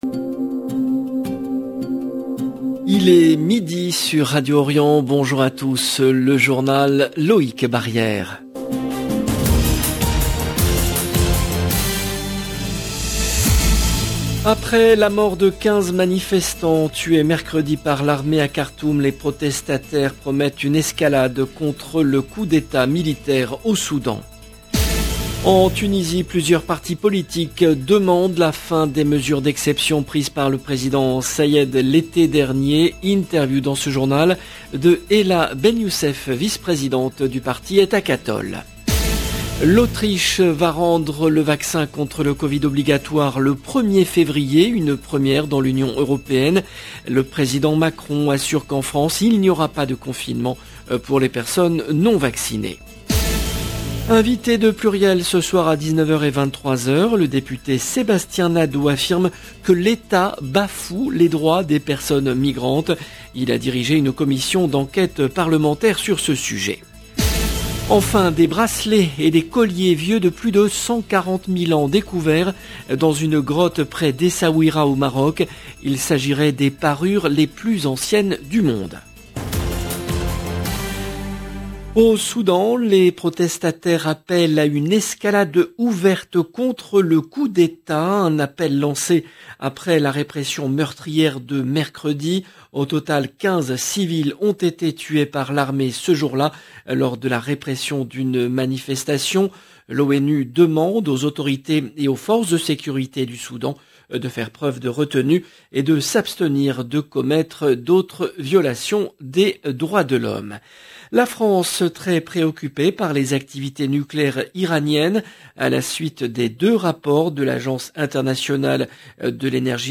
Soudan Tunisie immigration covid Autriche 19 novembre 2021 - 16 min 15 sec LE JOURNAL DE MIDI EN LANGUE FRANCAISE DU 19/11/21 LB JOURNAL EN LANGUE FRANÇAISE Après la mort de 15 manifestants tués mercredi par l’armée à Khartoum, les protestataires promettent une escalade contre le coup d’Etat militaire.